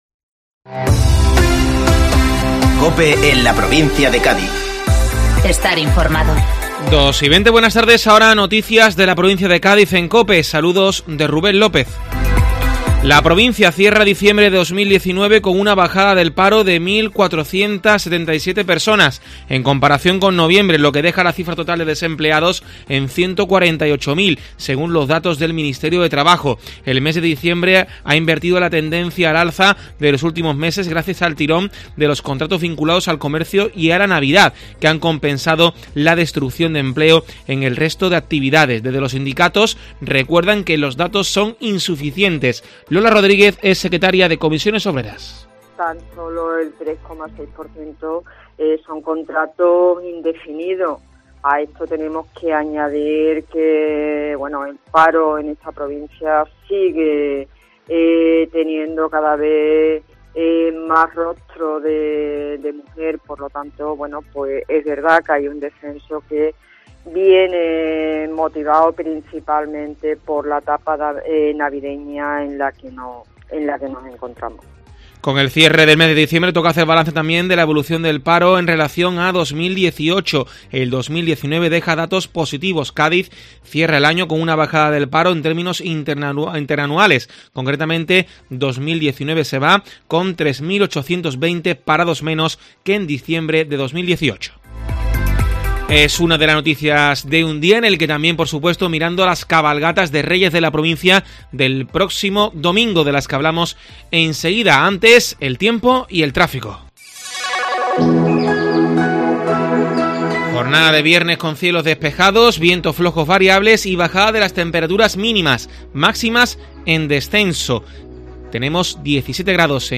Informativo Mediodía COPE Provincia de Cádiz (3-1-2020)